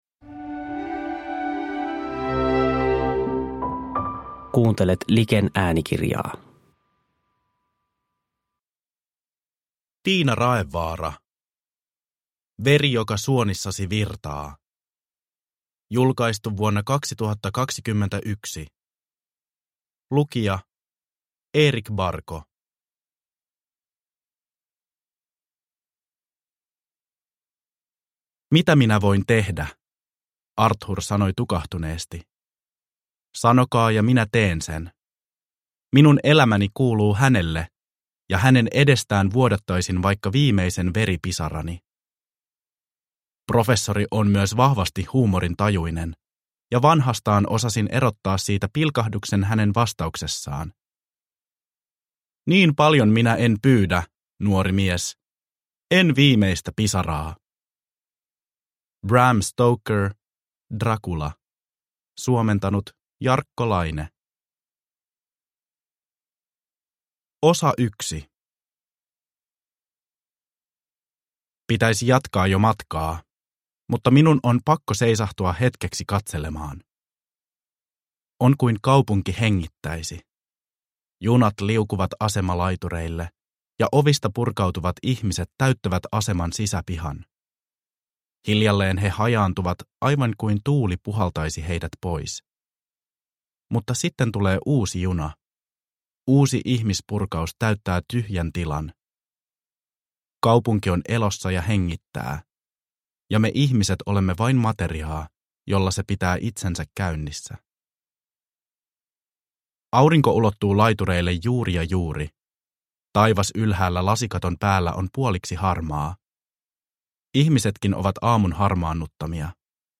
Veri joka suonissasi virtaa – Ljudbok – Laddas ner